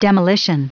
Prononciation du mot demolition en anglais (fichier audio)
Prononciation du mot : demolition